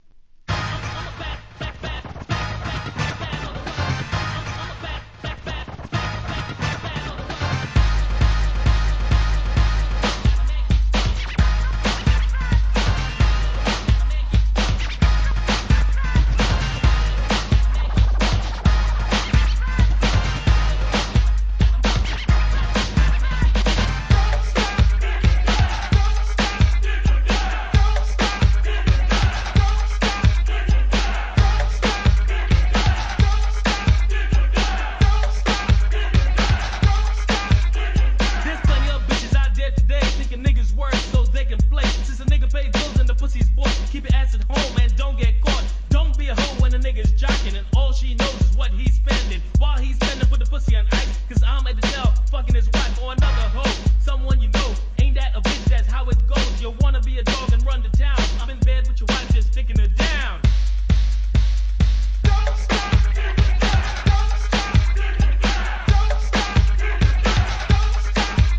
G-RAP/WEST COAST/SOUTH
FUNKYマイアミ・ミドル!!!